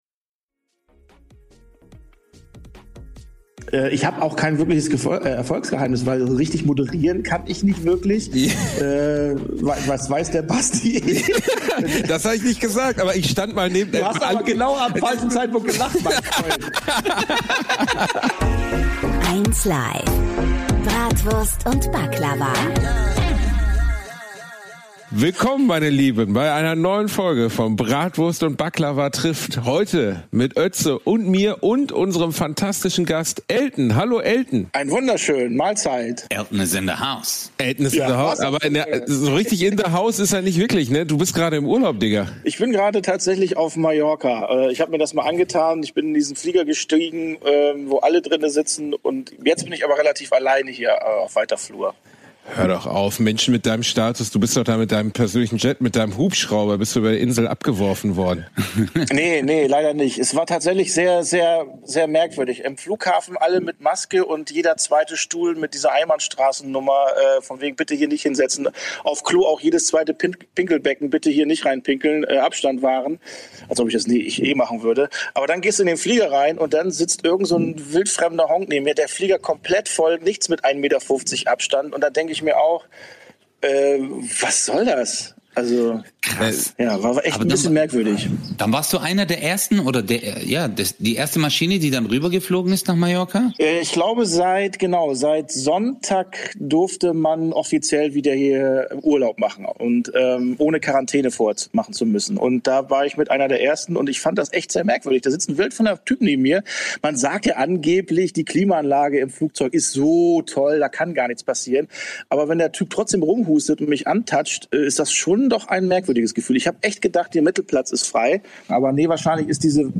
#46 Gast Elton - Live von Mallorca ~ Bratwurst und Baklava - mit Özcan Cosar und Bastian Bielendorfer Podcast